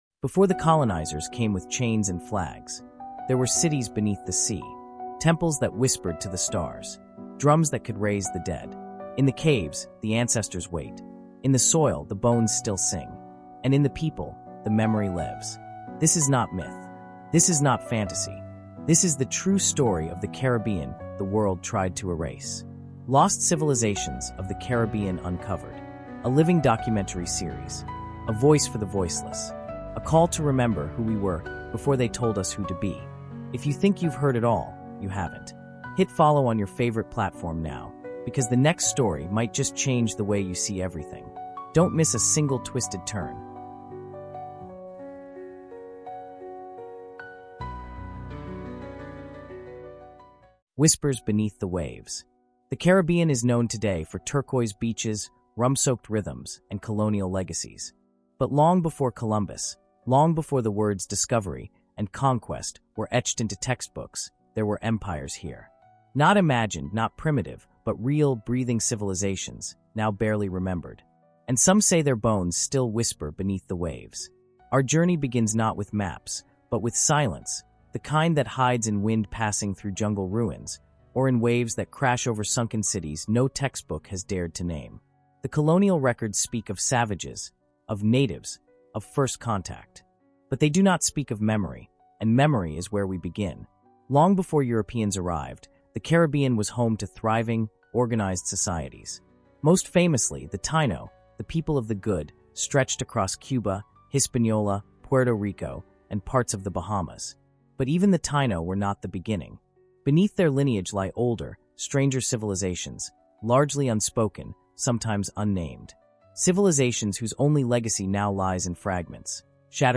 CARIBBEAN HISTORY: Lost Civilizations of the Caribbean Uncovered is a cinematic audio journey through the untold stories of ancient Caribbean civilizations. Blending rich soundscapes and emotionally immersive narration, this series uncovers the hidden world of sunken cities, sacred star-aligned temples, and the ancestral resilience of Maroon communities, Kalinago warriors, and the Garifuna people. We go beyond mainstream history to explore the cultural resilience and identity of the Caribbean, shedding light on the indigenous legacies buried under colonial conquest.